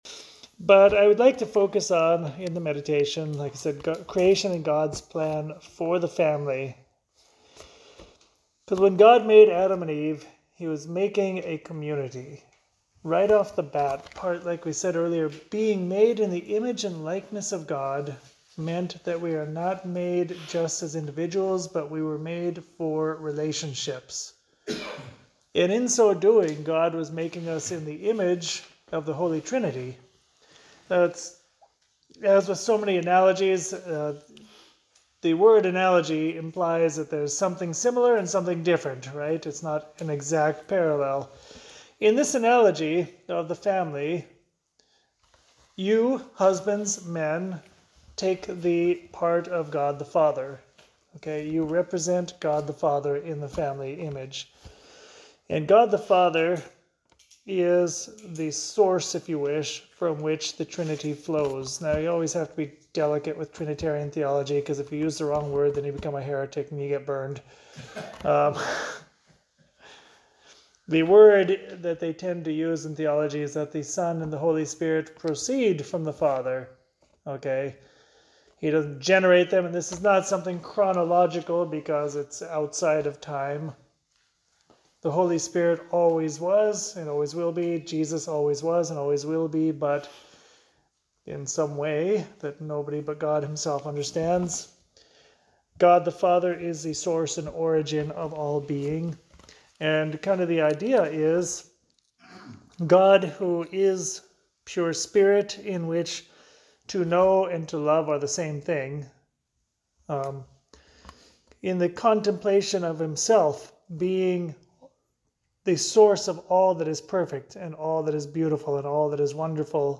meditation from the Men’s Spiritual Exercise retreat, on March 16th.